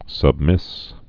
(səb-mĭs)